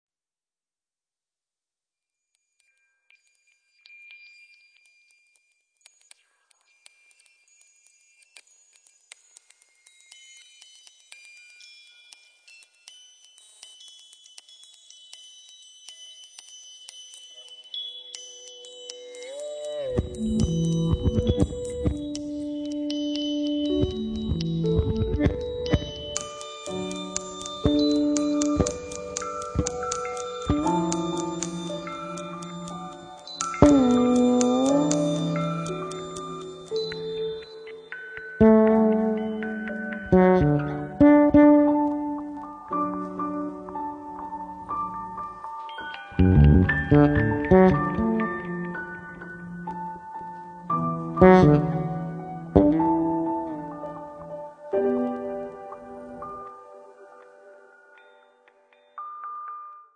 pianoforte, samplers, laptop, moog piano system
contrabbasso, basso elettrico
batteria